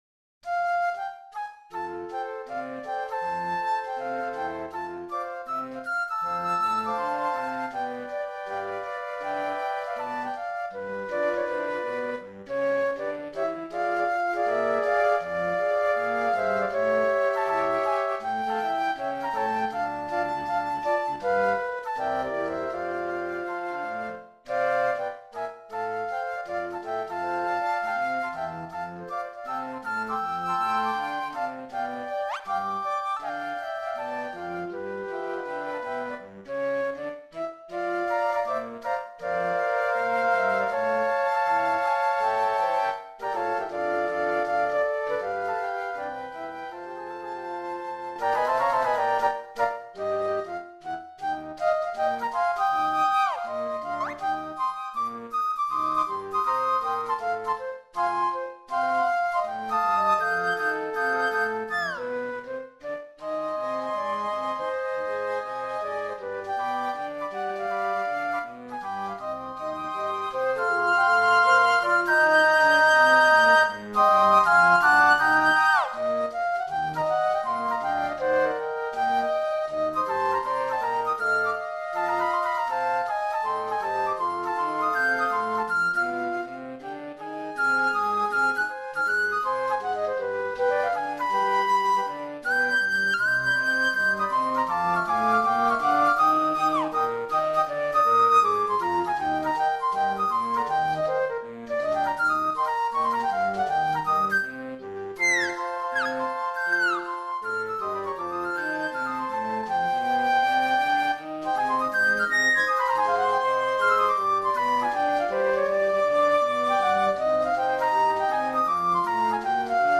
les-copains-dabord-Ensemble-de-Flûtes.mp3